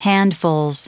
Prononciation du mot handfuls en anglais (fichier audio)
Prononciation du mot : handfuls